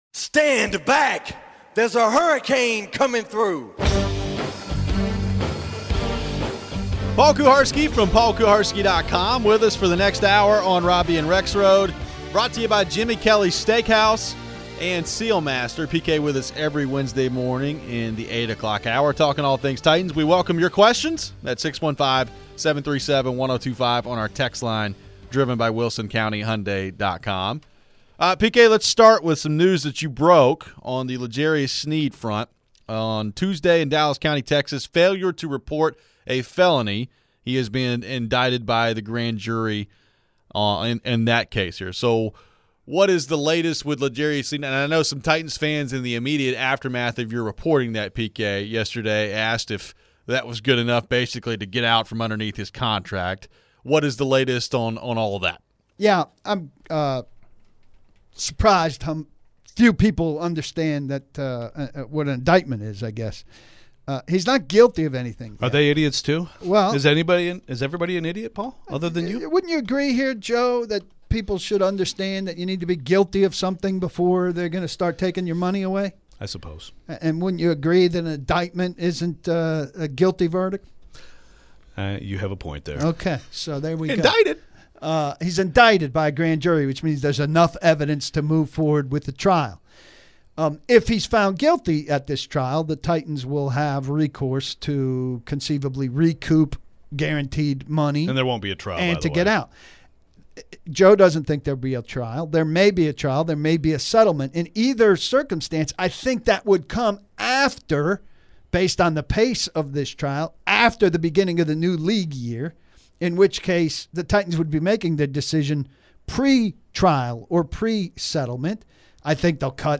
What is the #1 thing we want to see from Cam Ward in the 2nd half of the season? We talk a little too much about the draft, and take your phones.